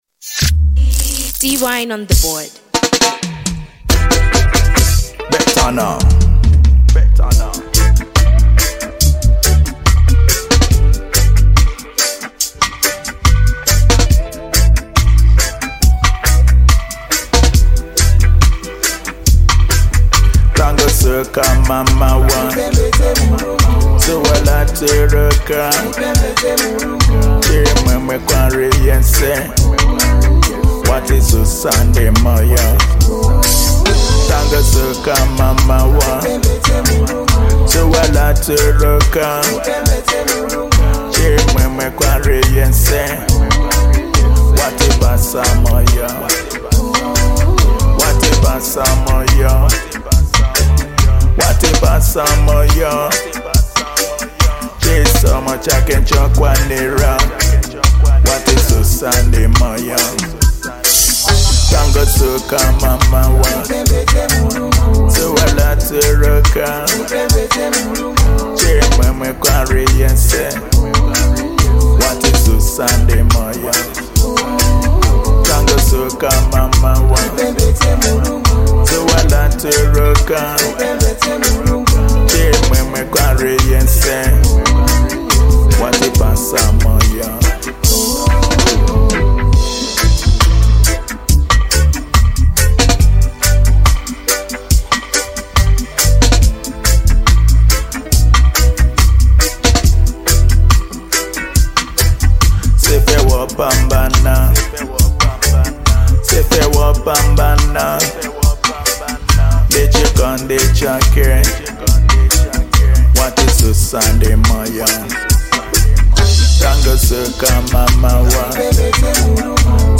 Genre : Reggie Dancehall